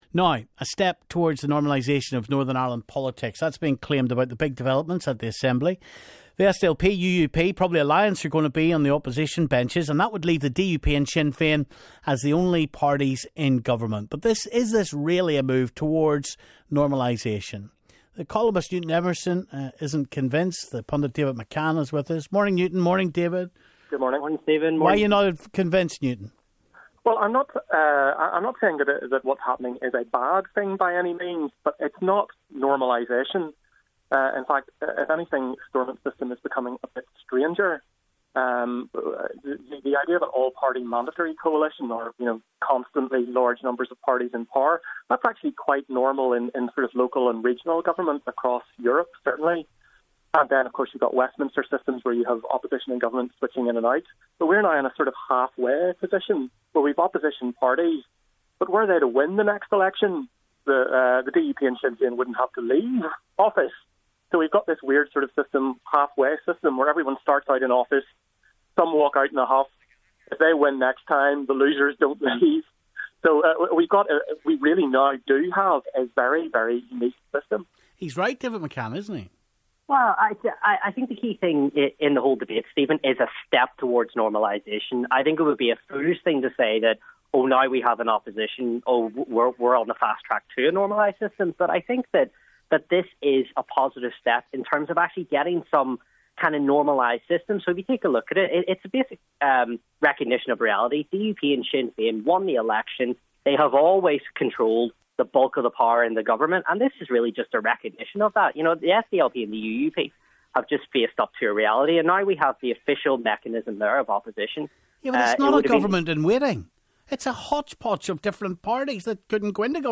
But is this really a move towards normalisation? Columnist
pundit